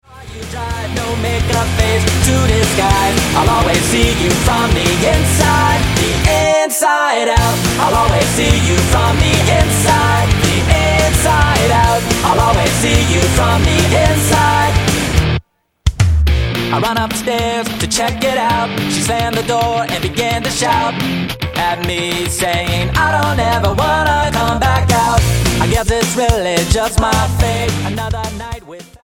STYLE: Pop
the punchy, pop rocker